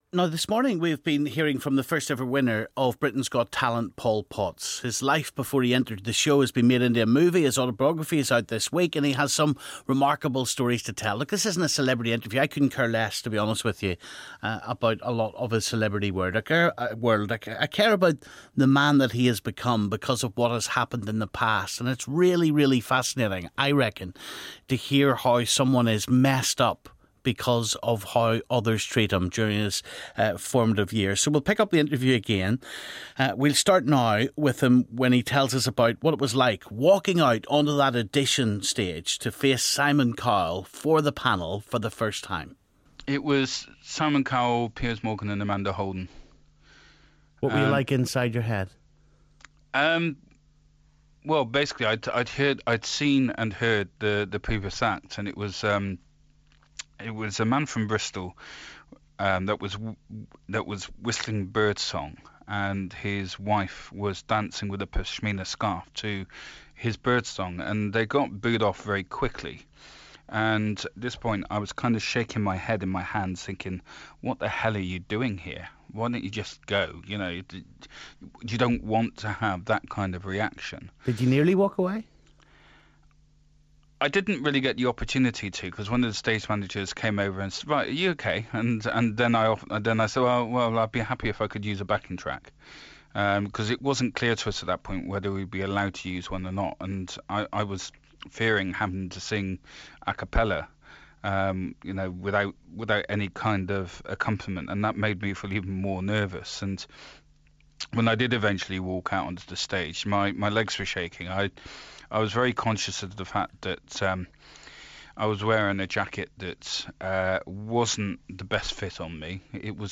Part 2 of our moving interview with Britain's Got Talent winner Paul Potts